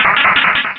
pokeemerald / sound / direct_sound_samples / cries / ludicolo.aif